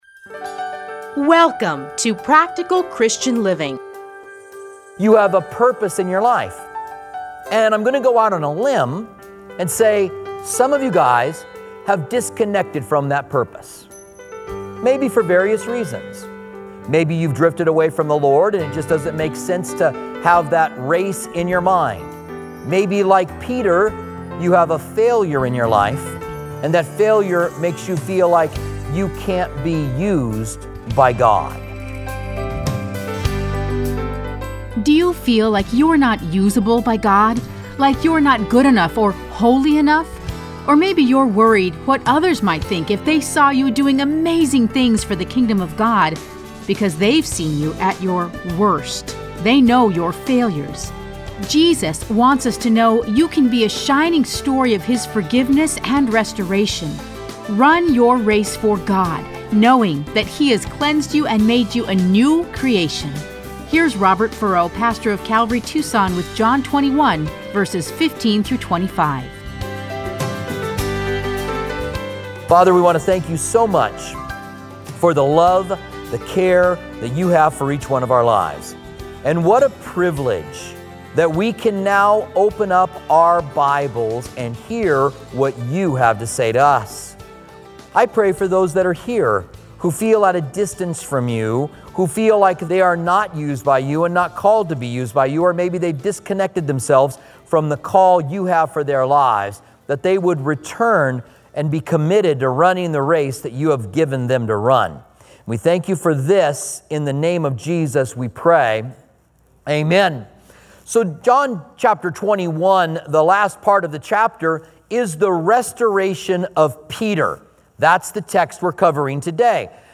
Listen to a teaching from John 21:14-25.